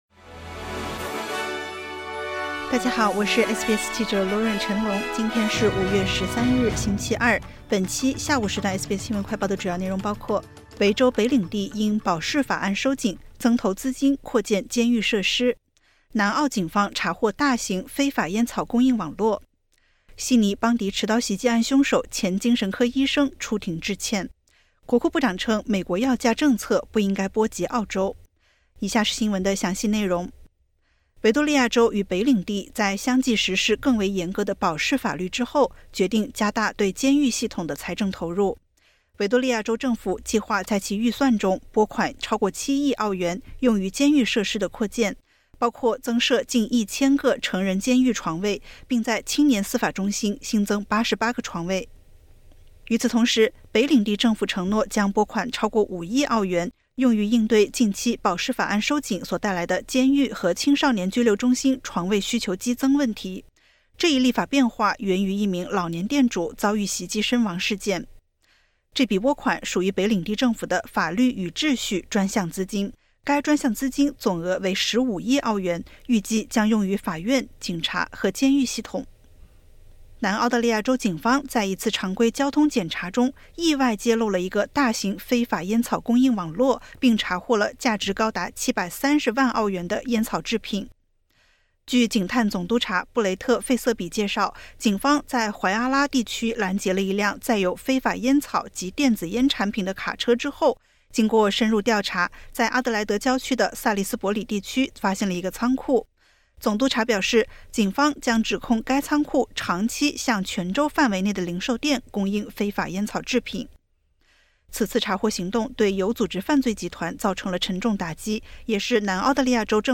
Source: AAP / AAPIMAGE SBS 新闻快报 View Podcast Series 下载SBS Audio应用程序 其他收听方式 Apple Podcasts  Spotify  Download (2.95MB)  2025年5月13日下午：维多利亚州与北领地在相继实施更为严格的保释法律后，决定加大对监狱系统的财政投入（收听播客，了解详情）。